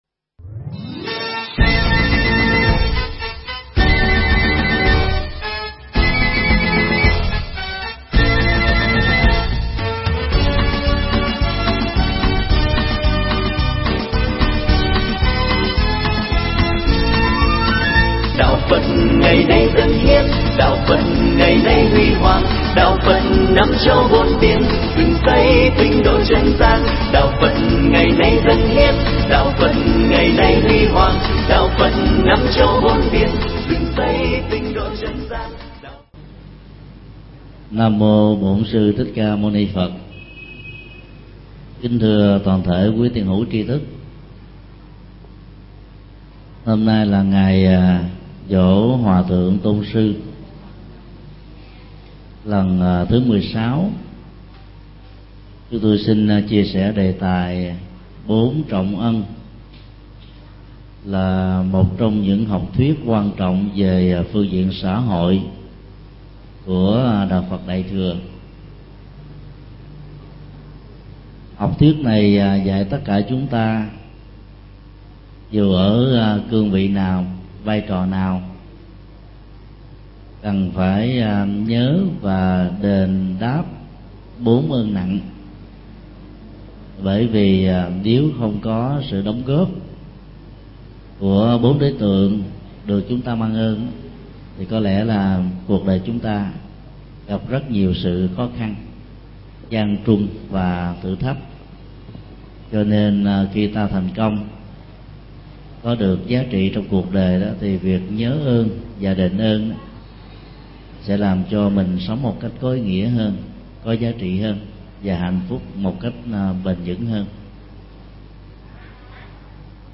Mp3 Thuyết Giảng Bốn Ân Lớn – Thượng Tọa Thích Nhật Từ giảng tại chùa Giác Ngộ ngày 30 tháng 8 năm 2008